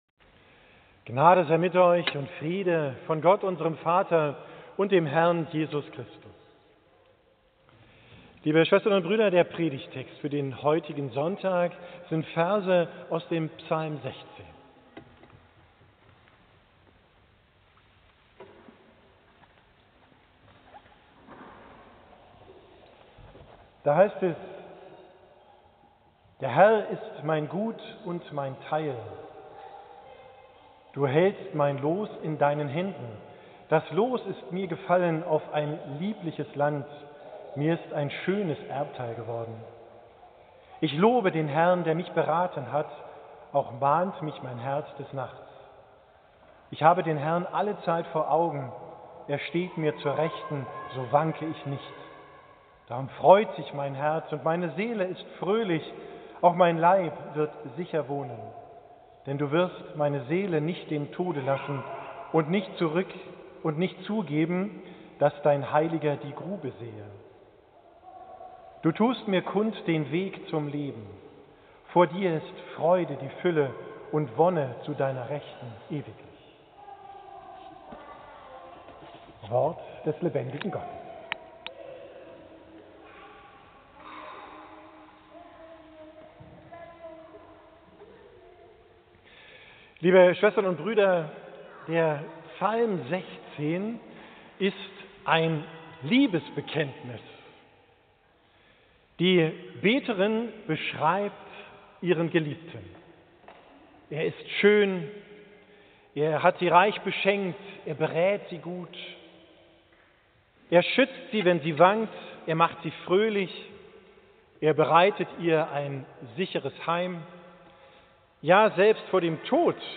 Predigt vom 16.